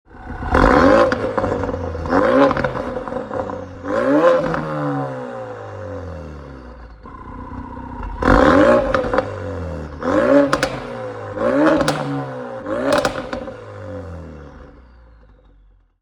• 2.3L 4 Cylinder EcoBoost Petrol Engine
• Milltek Catback Exhaust System (£1565)
Nitrous-blue-focus-rs-revs.mp3